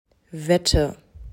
z.B. Katze, Wette
wette.m4a